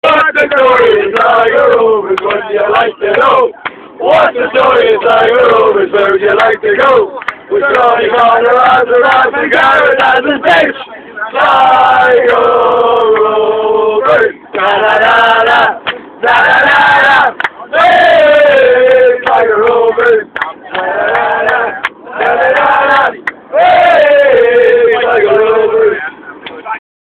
2 new chants. learn them well.